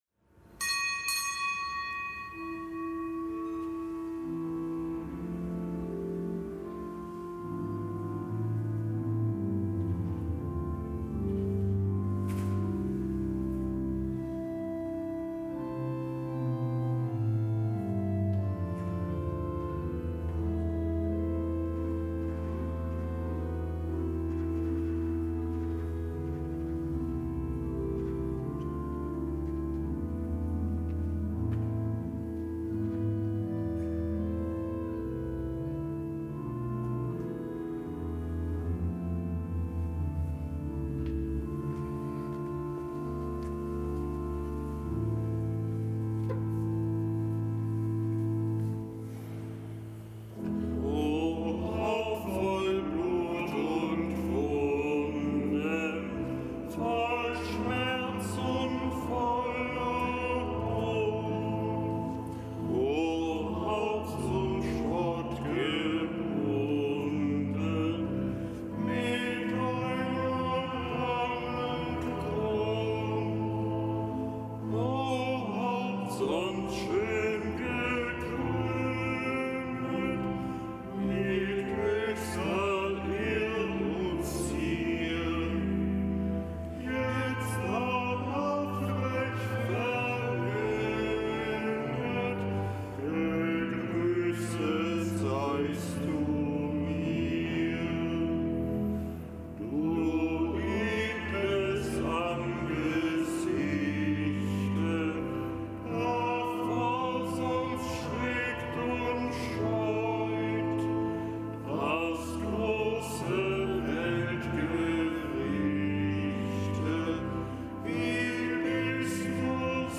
Kapitelsmesse am Donnerstag der fünften Fastenwoche